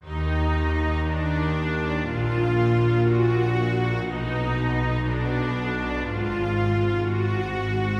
描述：管弦乐的弦乐演奏响亮而有力。
标签： 120 bpm Cinematic Loops Strings Loops 1.35 MB wav Key : D
声道立体声